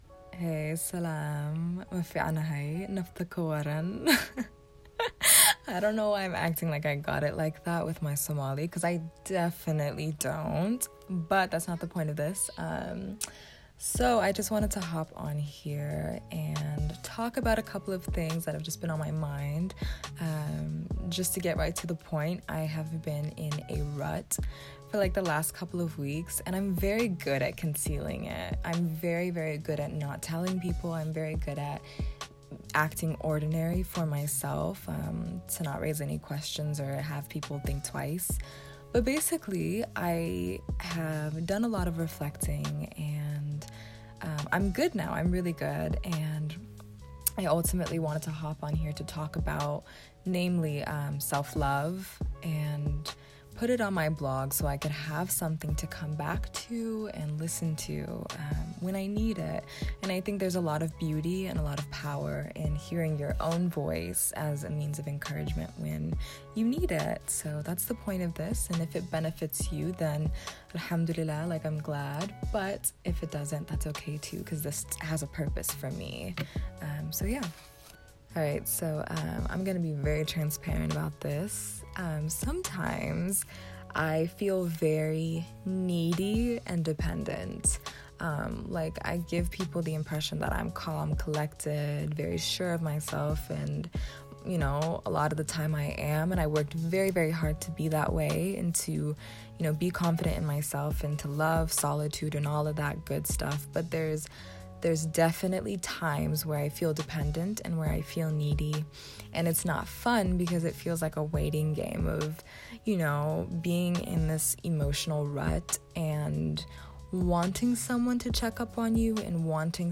This is a message by me for me.